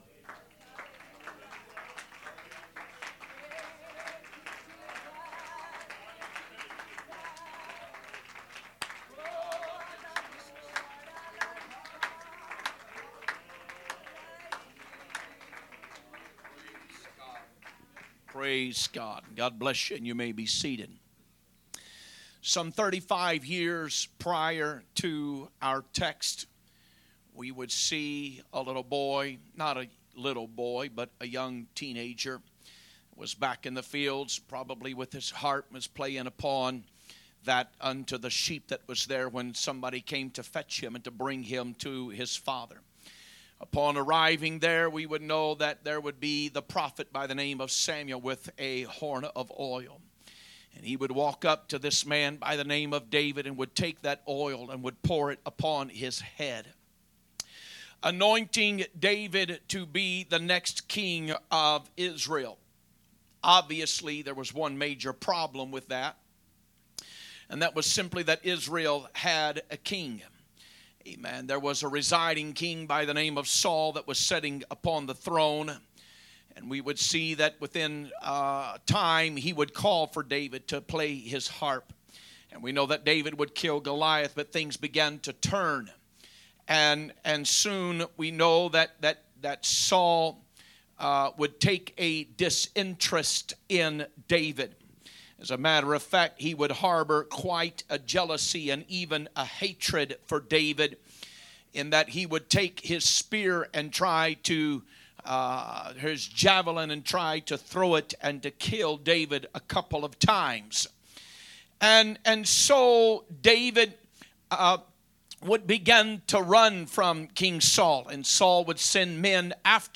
Wednesday Night Bible Study
2025 Sermons